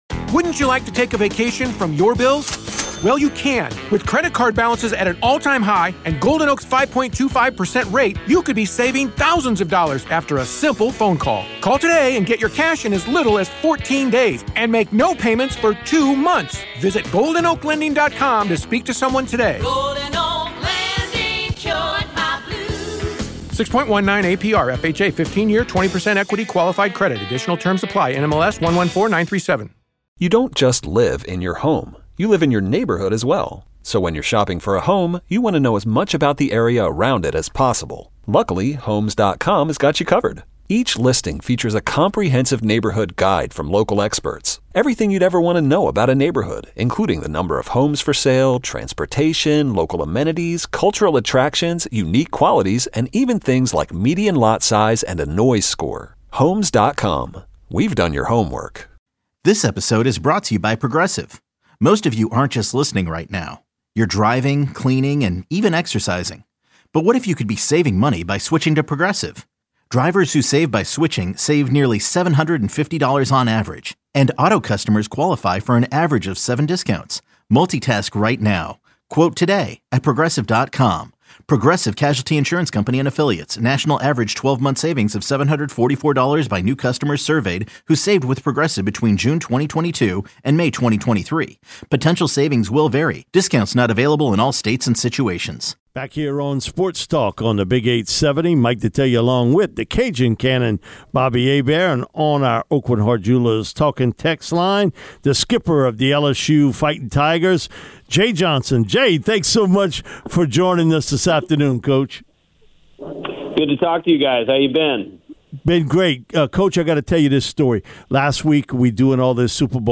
LSU interviews, press conferences and more